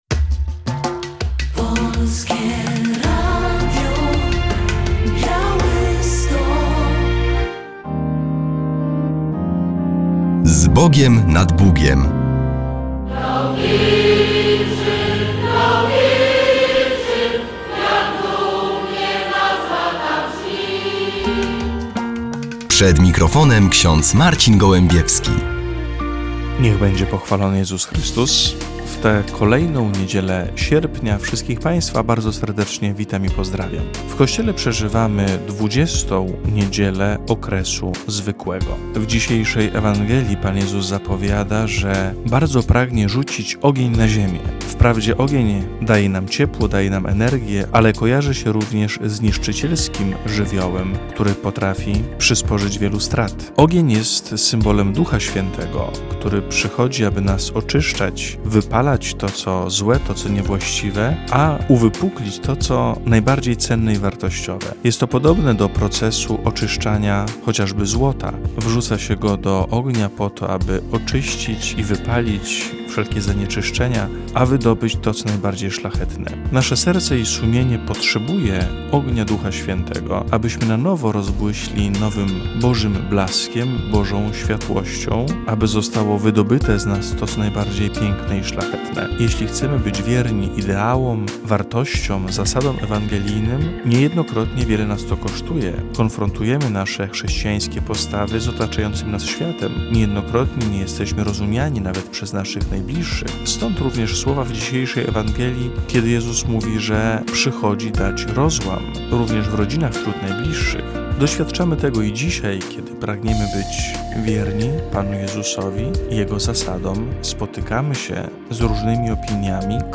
Relacja z obchodów święta Wojska Polskiego, które było obchodzone w Skrzeszewie, Czartajewie i Siemiatyczach